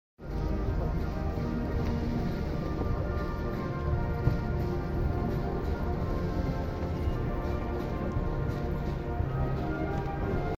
The Mp3 Sound Effect Trooping The Colour 2025. The King, The Colonels of the Regiments and Senior Officers on the move to Inspect the Line.